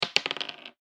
サイコロ・ダイス | 無料 BGM・効果音のフリー音源素材 | Springin’ Sound Stock
大きいシングルダイス1.mp3